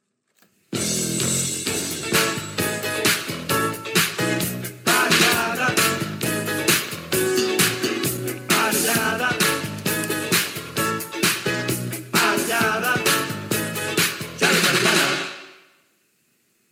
Indicatiu del locutor